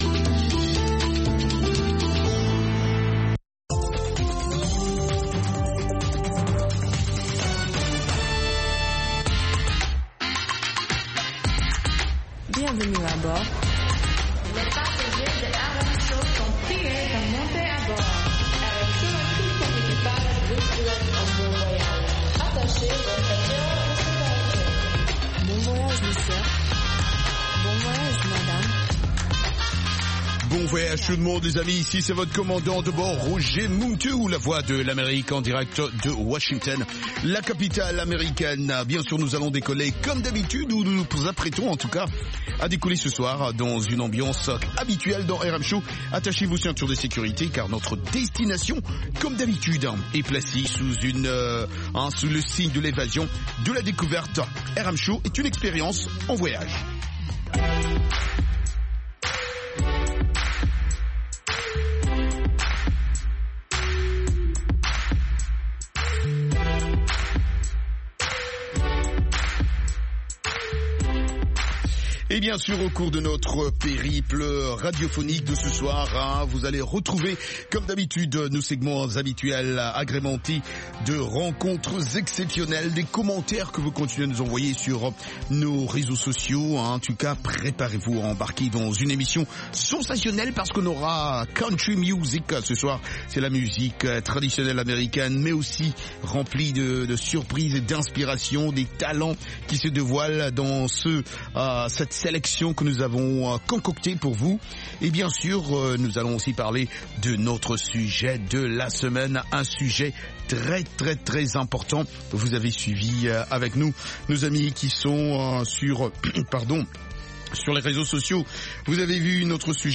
Balade musicale dans le monde entier, rions un peu avec de la comédie